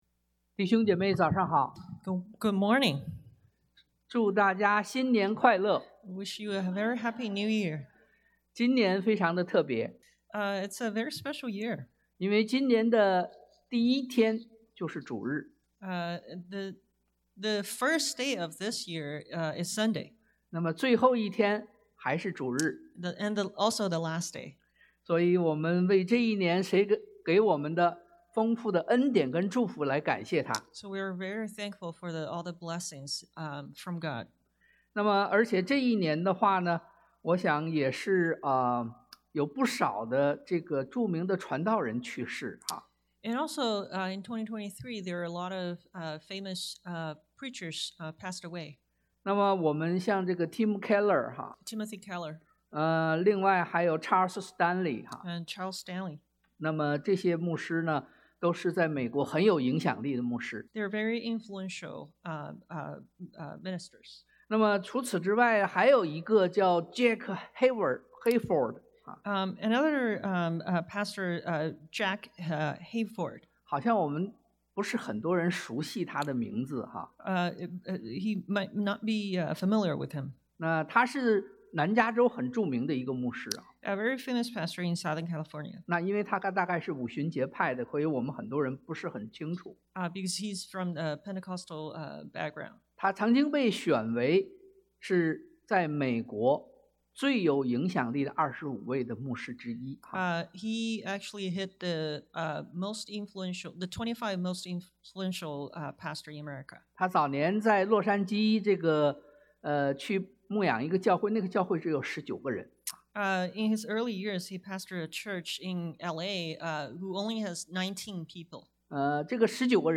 Passage: 腓Phil 1:3-11 Service Type: Sunday AM